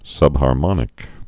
(sŭbhär-mŏnĭk)